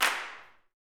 CLAPSUTC5.wav